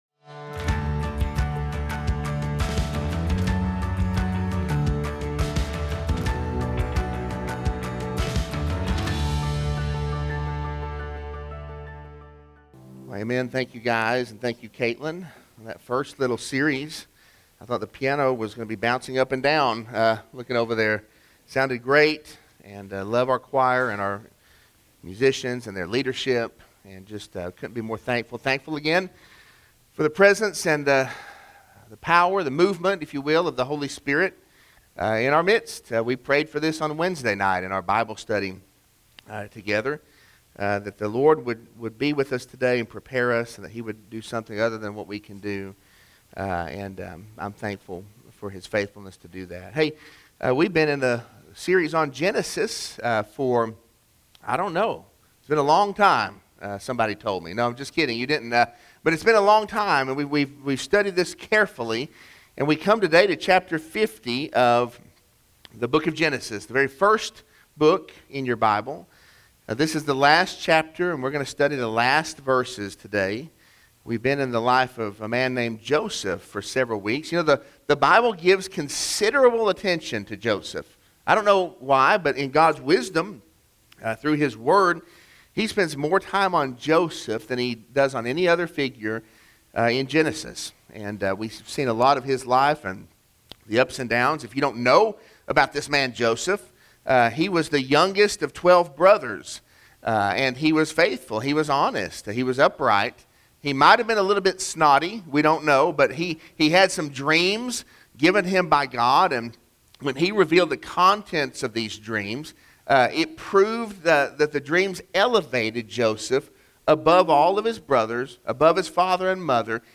Sermon-2-6-22-audio-from-video.mp3